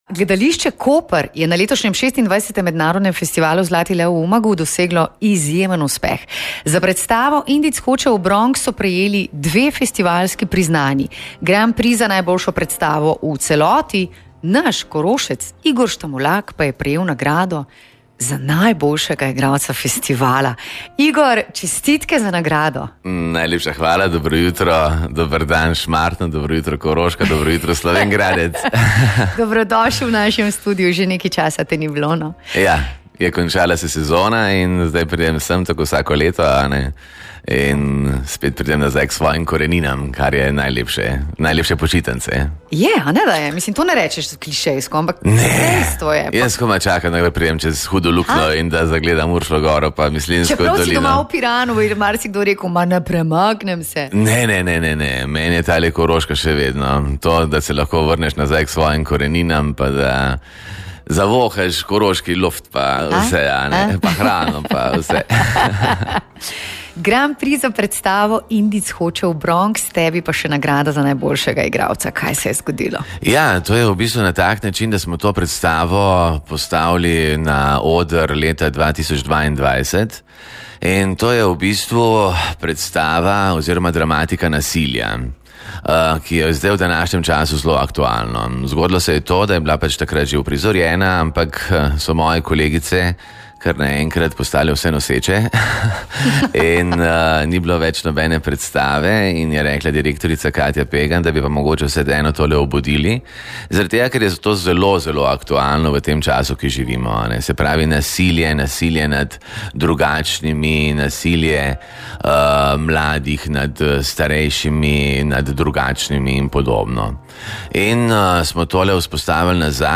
Pogovor z njim poslušajte tukaj: